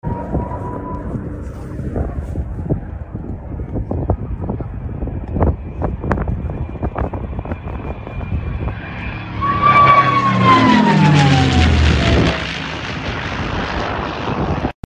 p 51 mustang iconic whistle (found sound effects free download
p-51 mustang iconic whistle (found this on discord)